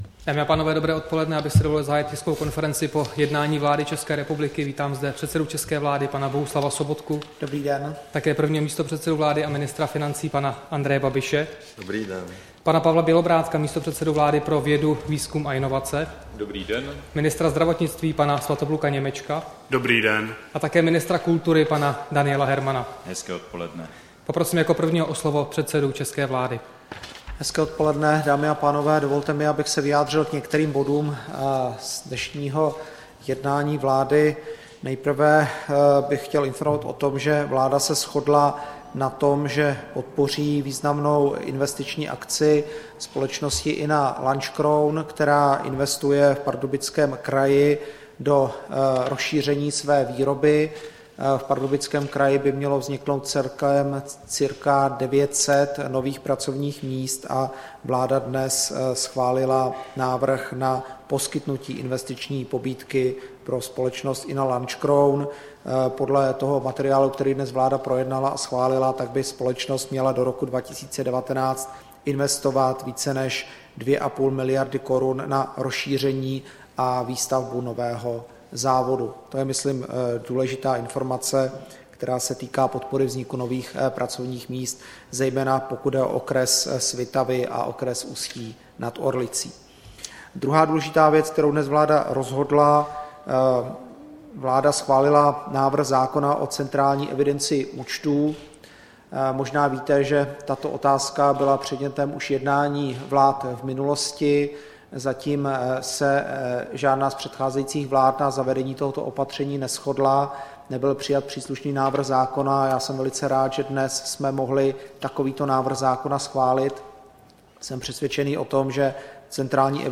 Tisková konference po jednání vlády, 3. února 2016